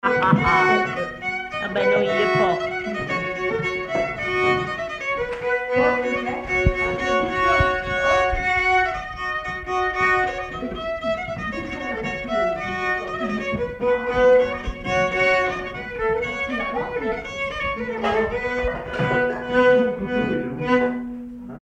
Avant-deux
circonstance : bal, dancerie
Pièce musicale inédite